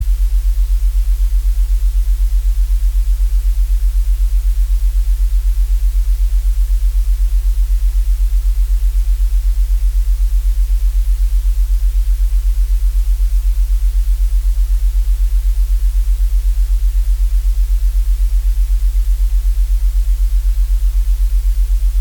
На этой странице собраны разнообразные звуки белого шума, включая классическое шипение, помехи от телевизора и монотонные фоновые частоты.
Звуки белого шума: Гипнотический эффект белого шума